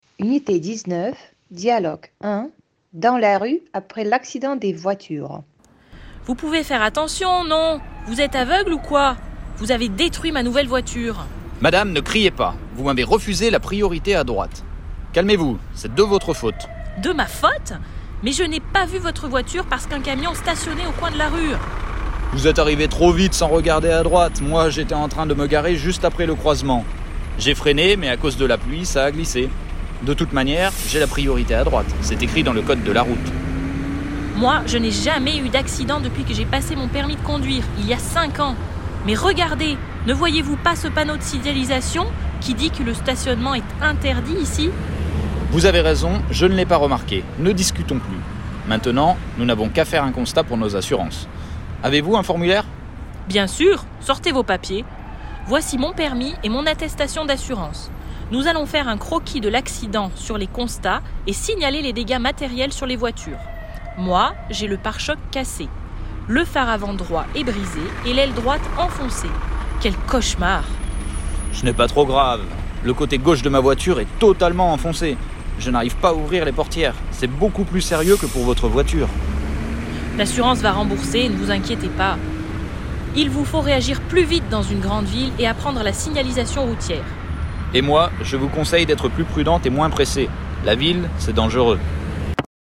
Dialogue 1 — Dans la rue après l’accident des voitures.